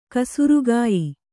♪ kasurugāyi